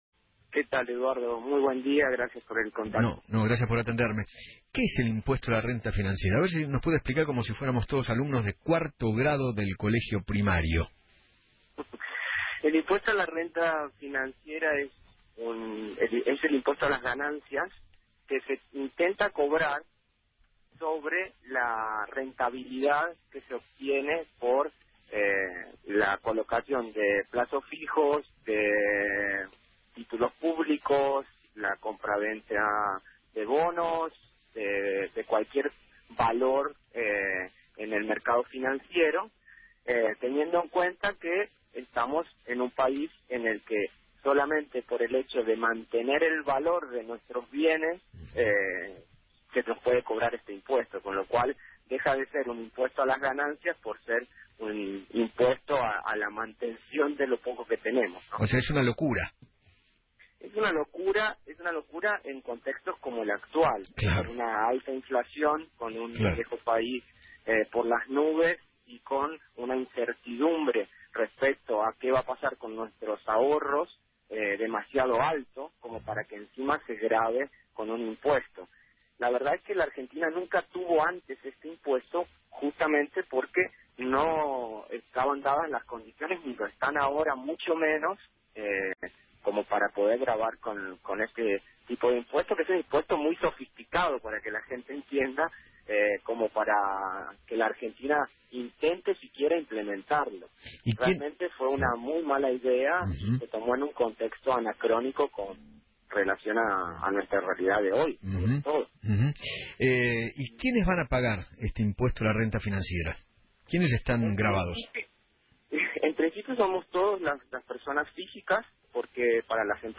habló en Feinmann 910